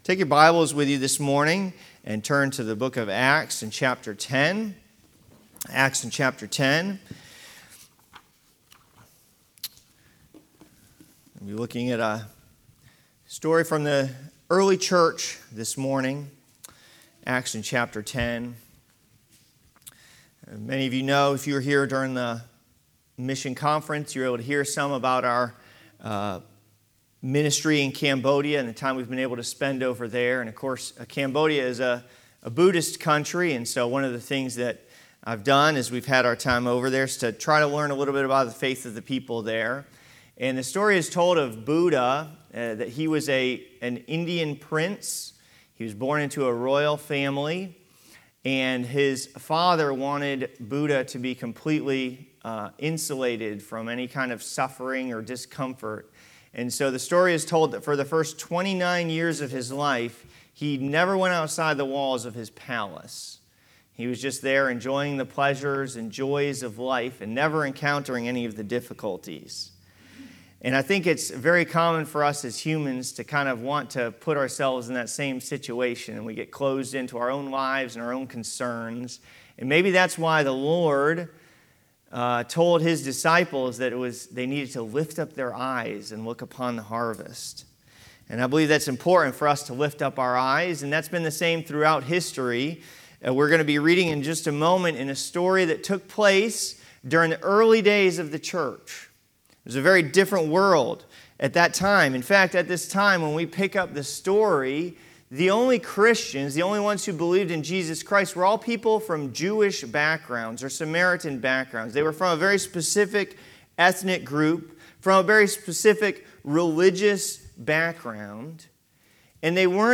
Mission Conference 2025 &middot